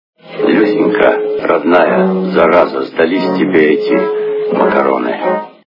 » Звуки » Из фильмов и телепередач » КИН-ДЗА-ДЗА - Люсенька-родная,зараза,сдались тебе эти..макароны
При прослушивании КИН-ДЗА-ДЗА - Люсенька-родная,зараза,сдались тебе эти..макароны качество понижено и присутствуют гудки.